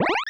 Heal.wav